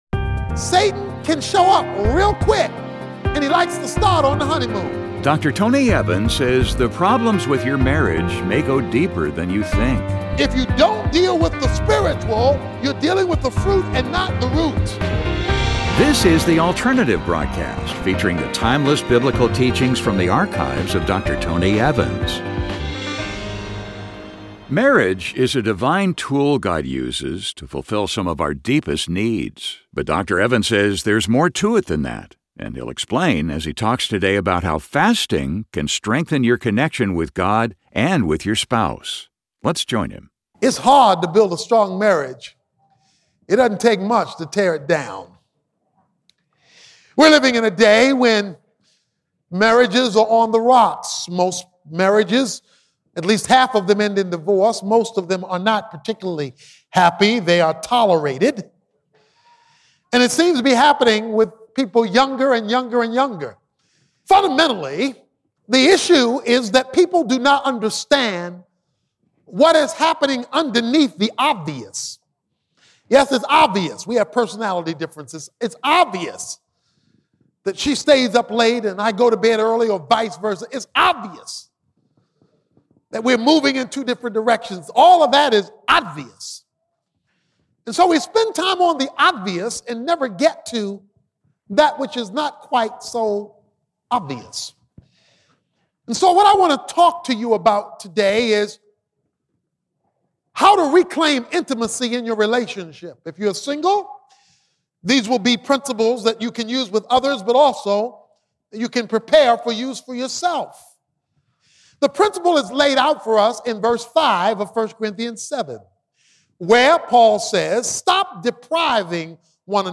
Fasting for Marriage Podcast with Tony Evans, PhD